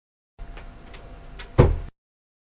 摔门声
描述：前门砰的一声